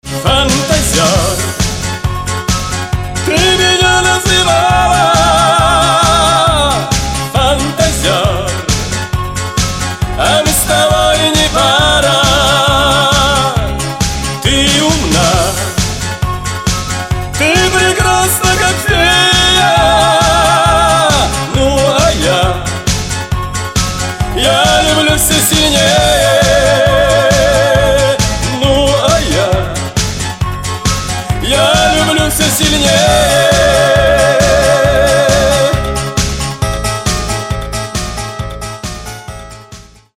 • Качество: 320, Stereo
поп
диско
ретро